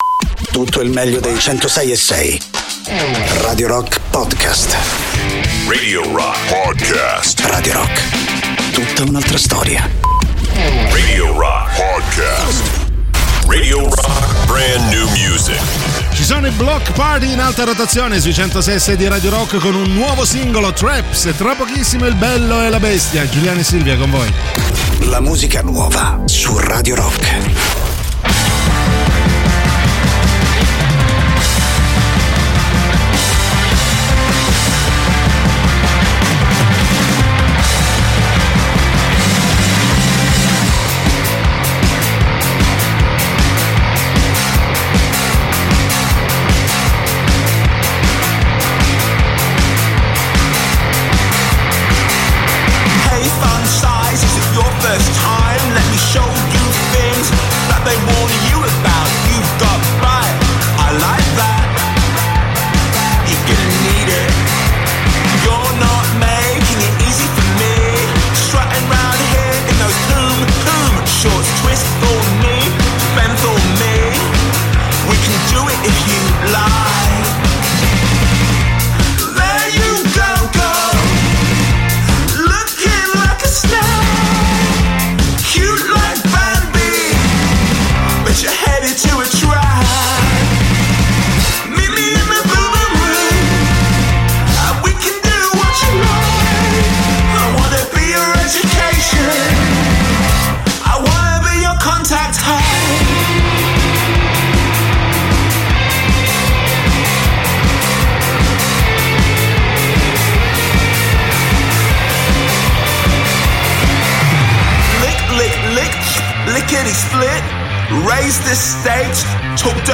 in diretta sui 106.6 di Radio Rock dal Lunedì al Venerdì dalle 13.00 alle 15.00.